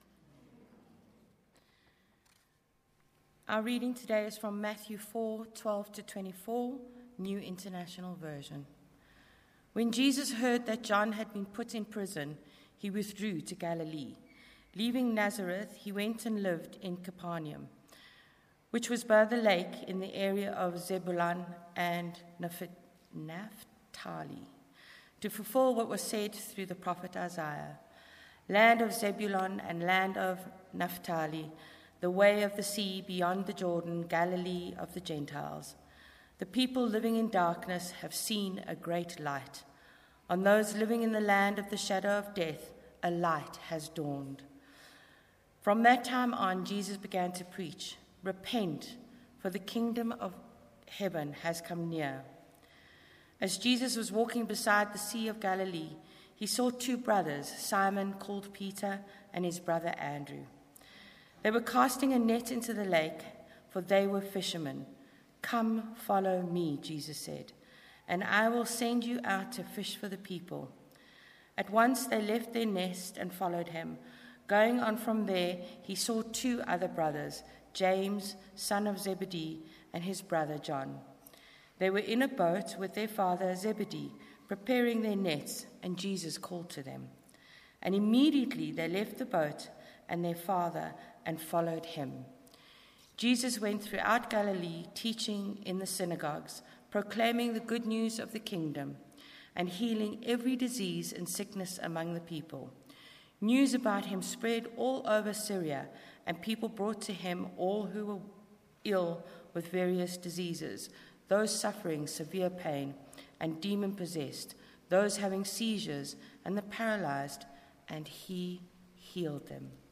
9:30am Fusion Service from Trinity Methodist Church, Linden, Johannesburg
Sermons